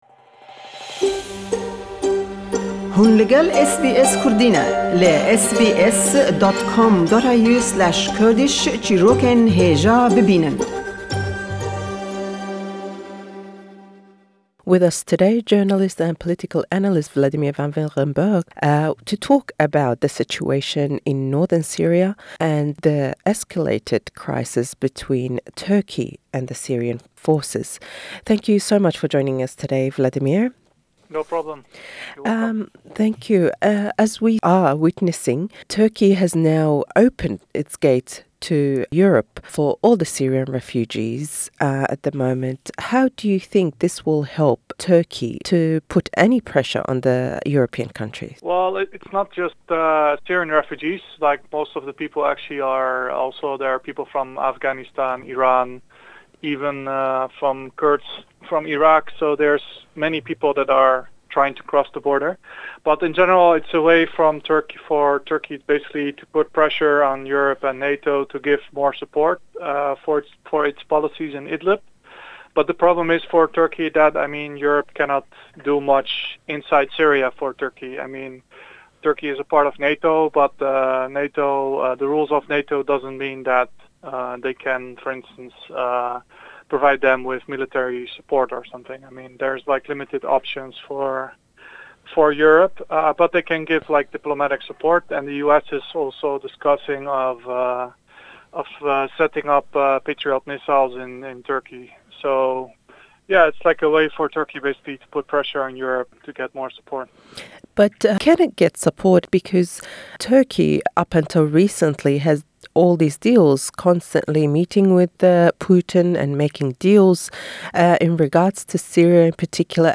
Le em lêdwane da